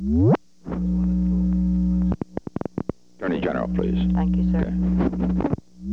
Secret White House Tapes
Conversation No. 11-34
Location: White House Telephone
The President talked with the White House operator.